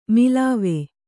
♪ milāve